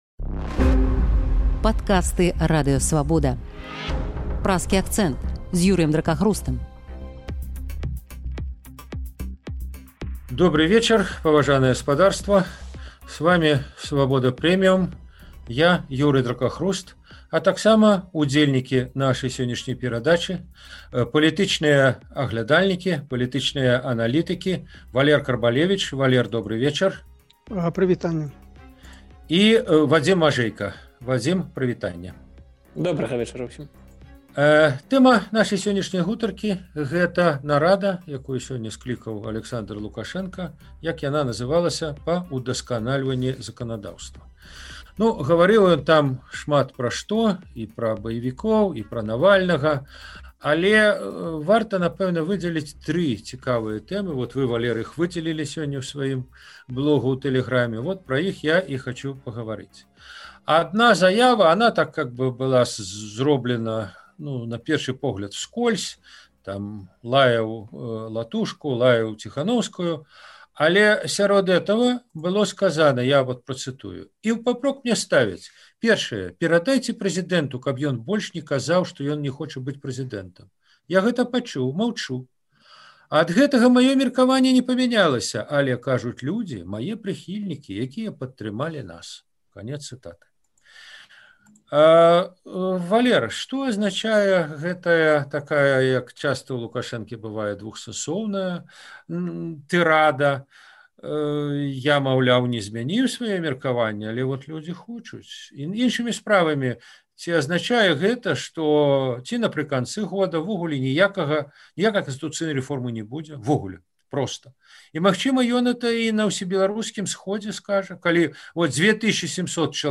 Гэтыя пытаньні ў Праскім акцэнце абмяркоўваюць палітычныя аналітыкі